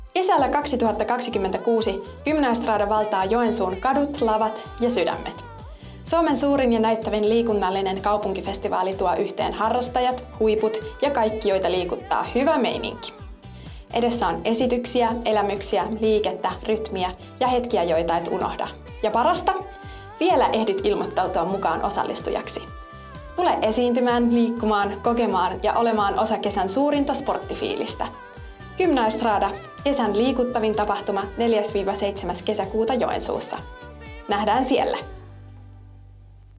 Gymnaestrada-äänimainos 1 - osallistujat - Materiaalipankki